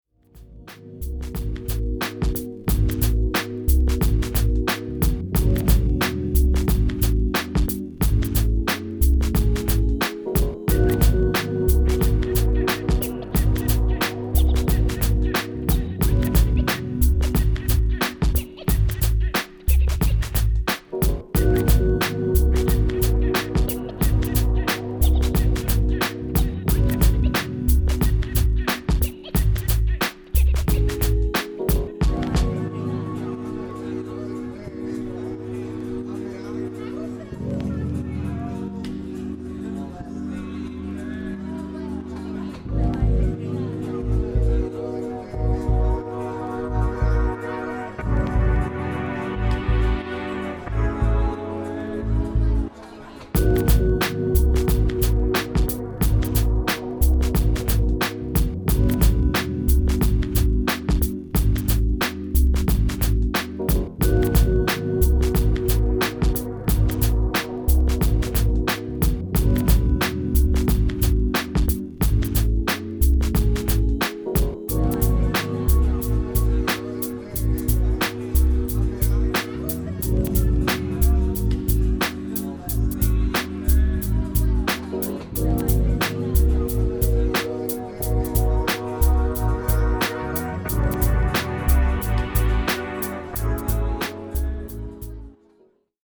5 tunes from deep house to down tempo